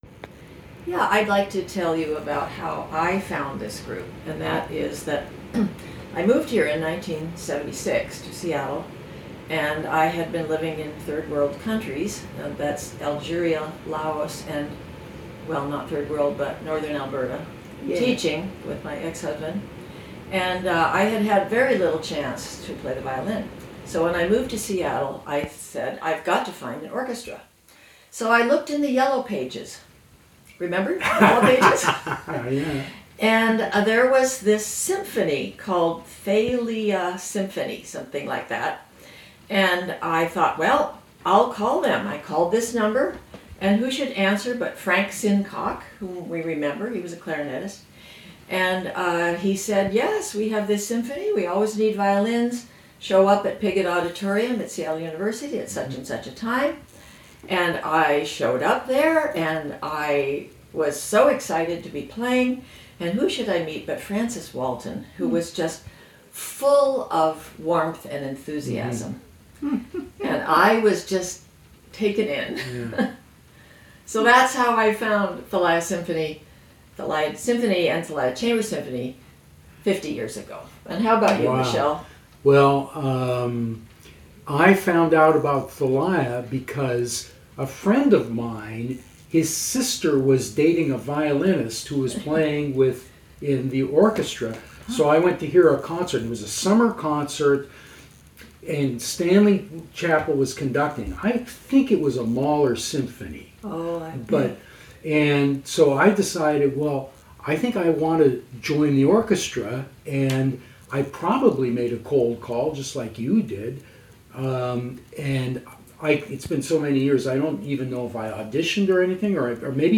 This conversation is about how the 50-year members